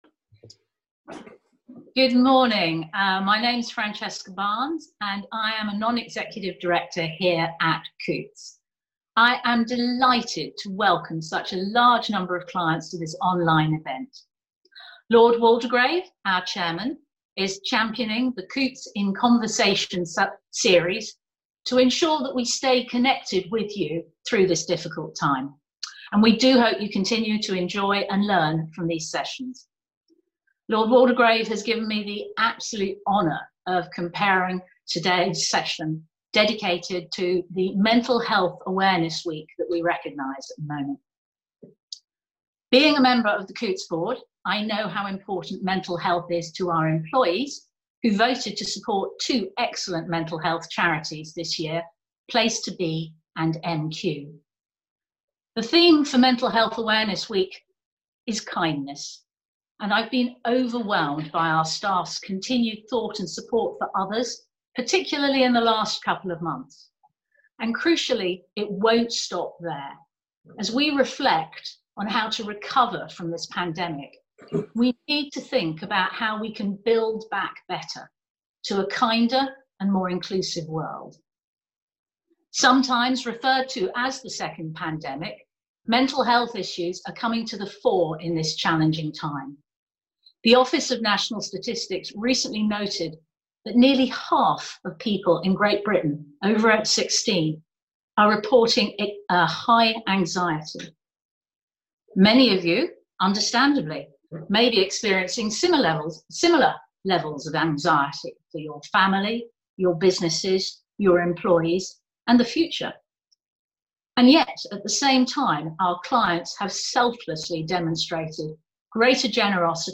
Humanitarian Terry Waite CBE and educator Sir Anthony Seldon talked about remaining resilient in isolation at the latest Coutts online event